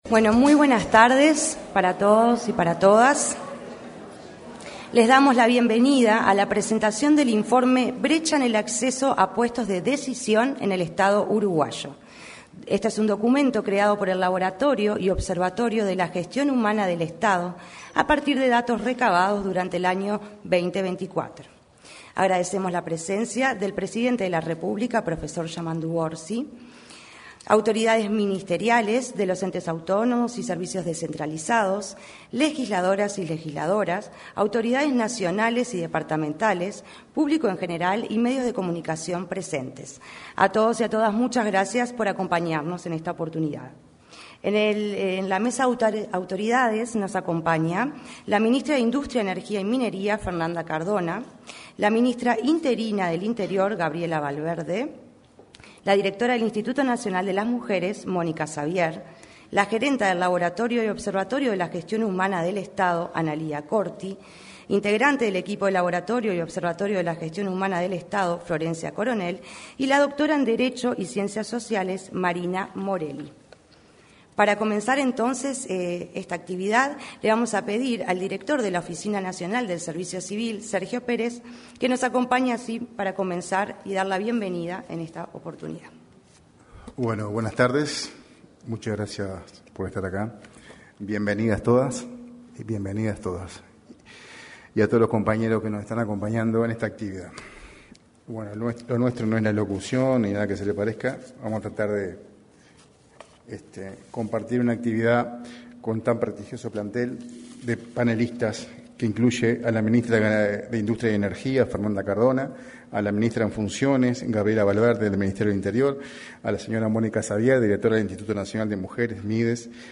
Presentación del informe Brecha de género en los puestos jerárquicos en el Estado uruguayo 31/03/2025 Compartir Facebook X Copiar enlace WhatsApp LinkedIn En el auditorio de Torre Ejecutiva se efectuó la presentación, por parte de la Oficina Nacional del Servicio Civil, (ONSC), del informe Brecha de género en los puestos jerárquicos en el Estado uruguayo.